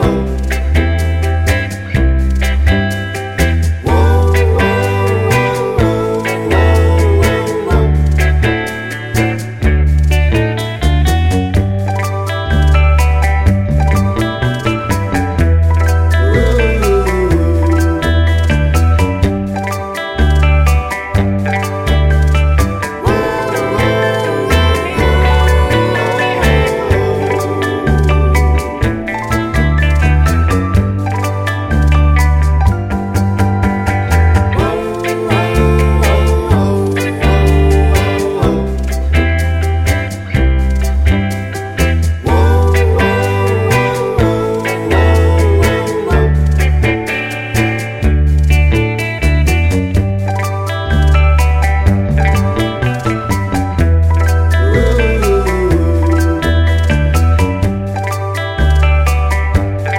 no Backing Vocals Country (Female) 2:16 Buy £1.50